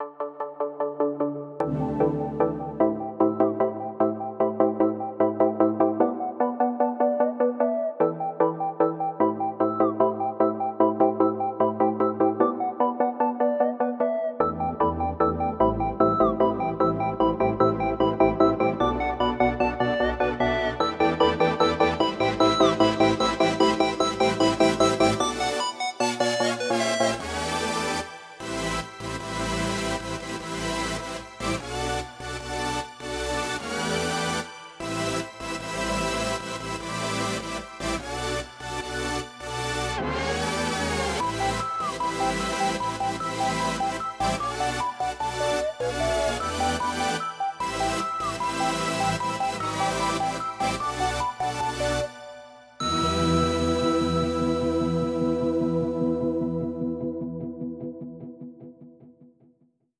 Let’s just take out all the music except the pads.